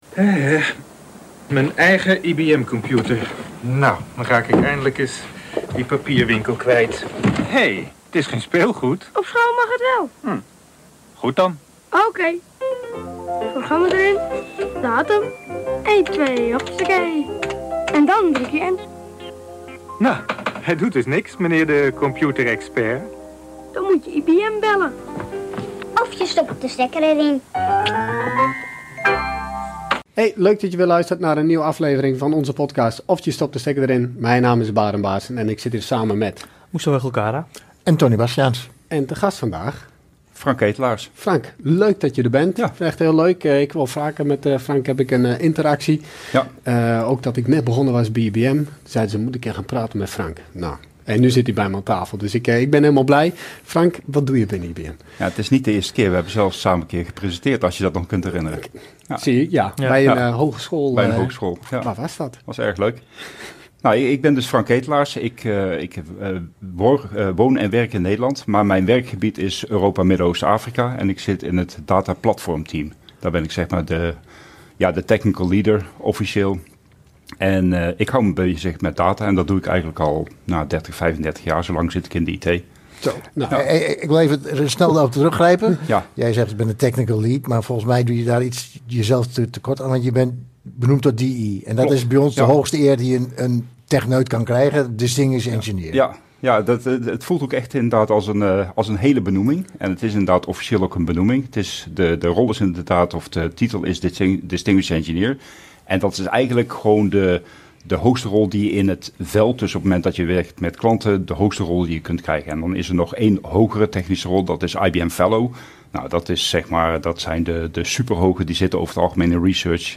samen met een gast technische ontwikkelingen bespreken. Meestal IBM Systems gerelateerd, maar zeker niet beperkt tot dat.